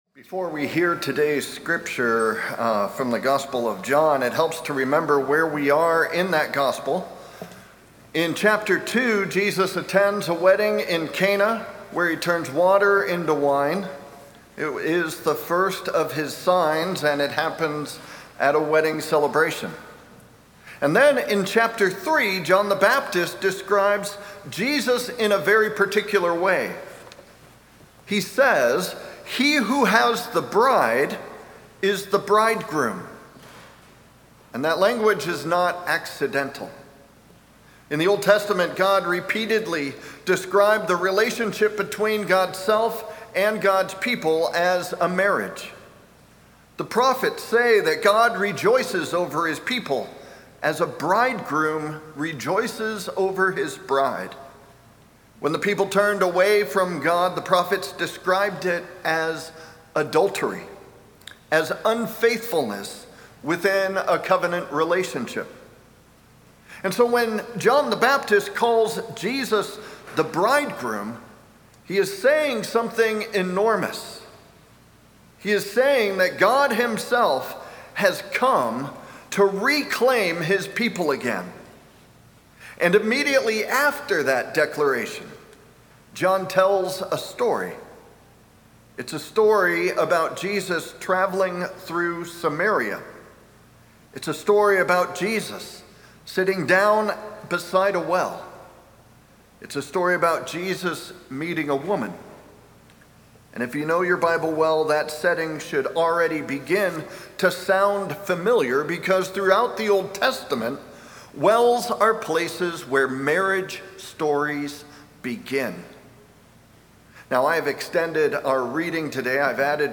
Sermon+3-8-26.mp3